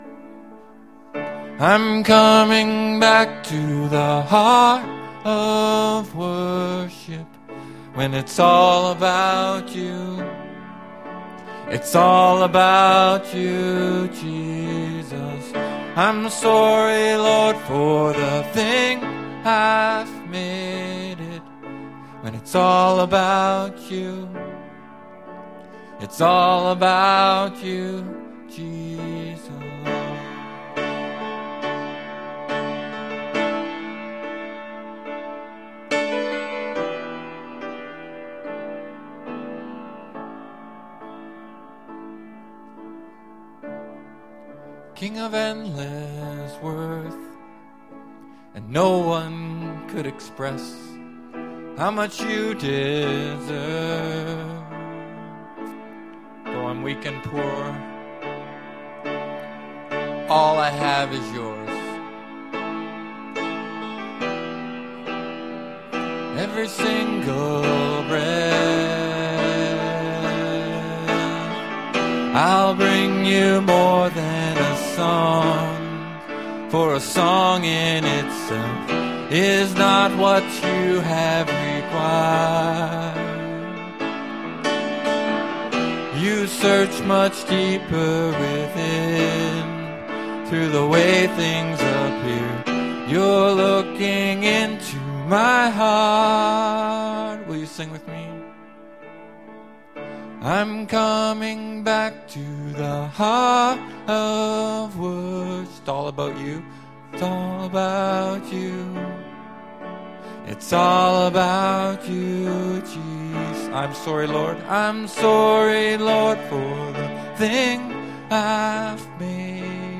This week's service is a bit different ... the whole service is included in the podcast in two sections.